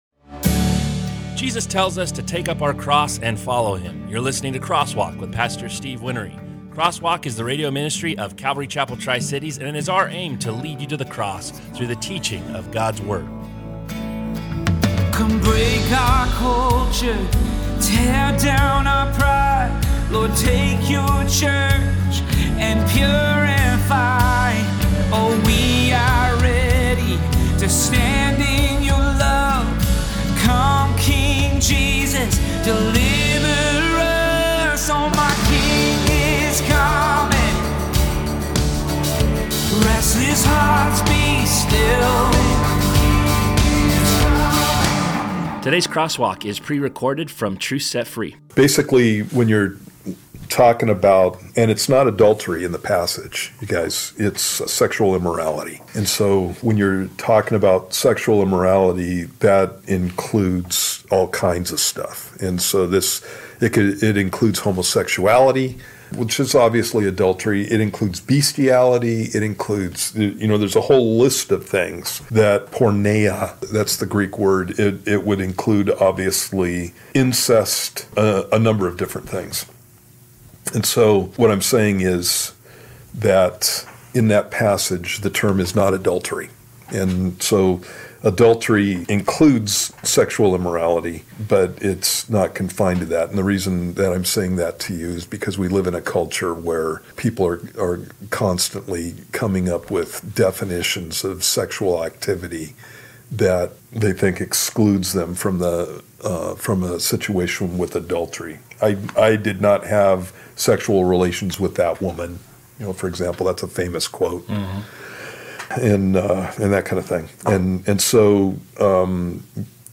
Crosswalk is a verse by verse bible study.